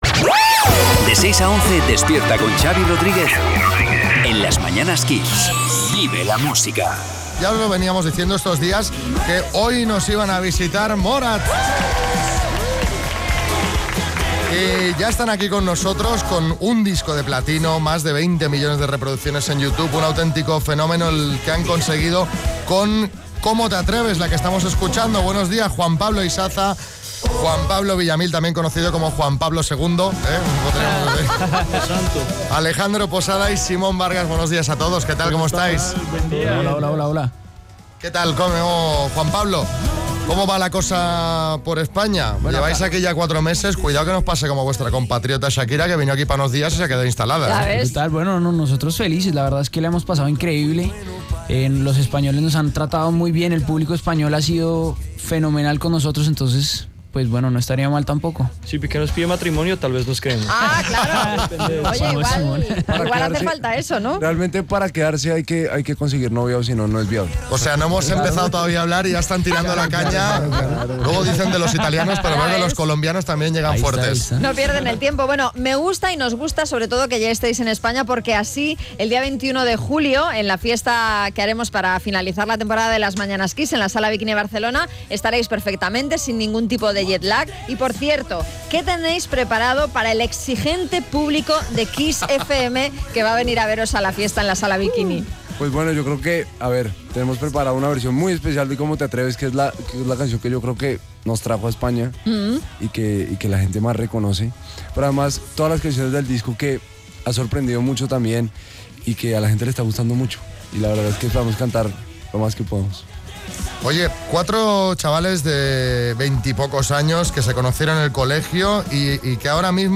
Entrevistamos a la banda revelación que actuará en directo en nuestra fiesta de final de temporada.